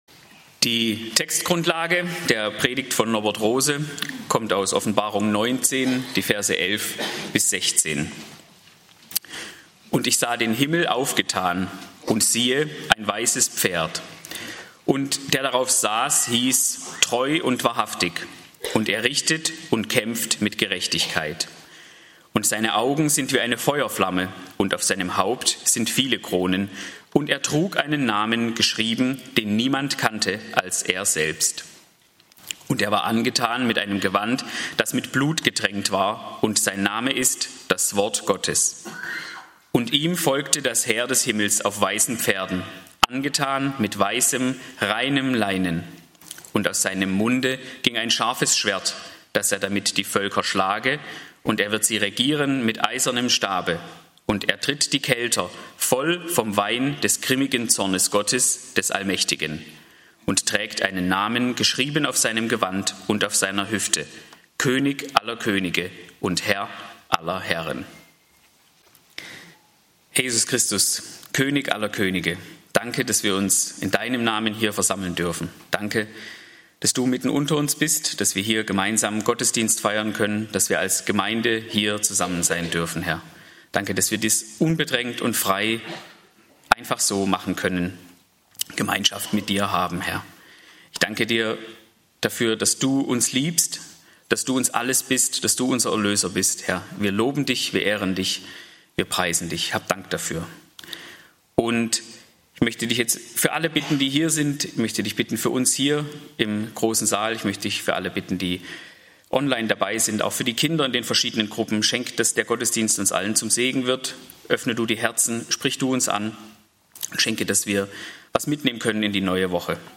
Siehe, er kommt mit den Wolken! (Offb 19, 11-16) - Gottesdienst